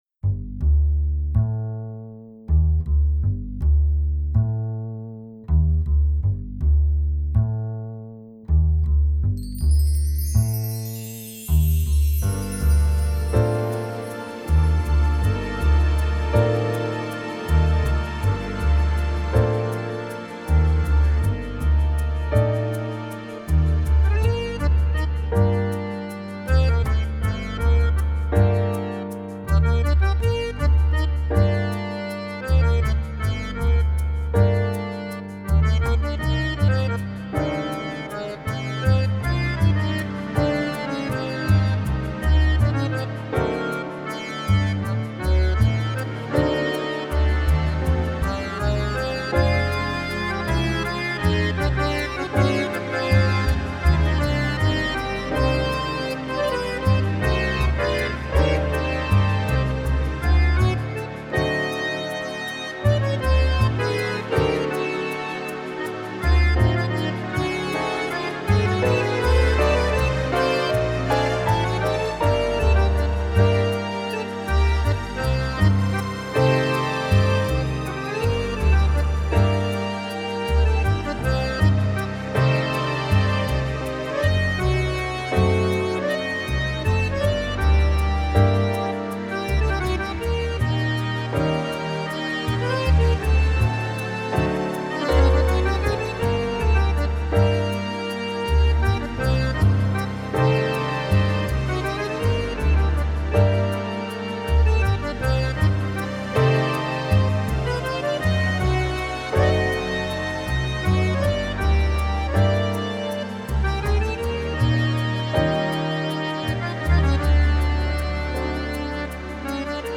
танго
В сентябре сделали переработку этой мелодии в более печально-щемящем настроении. Я назвал эту версию "Прощай, грусть..."
Выкладываю mp3 с предварительным сведением в аттачмент. Мне нравится партии аккордеона, фно и контрабаса.